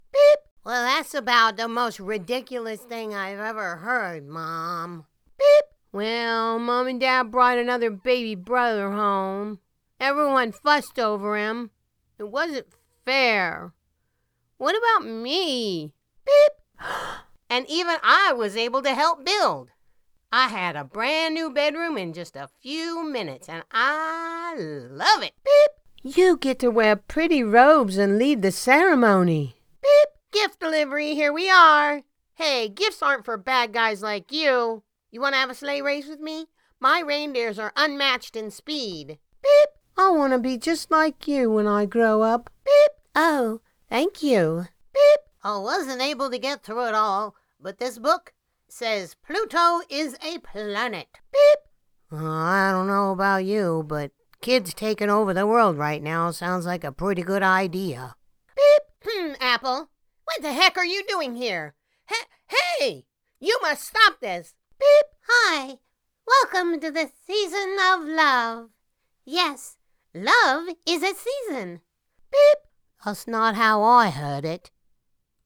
Young Voices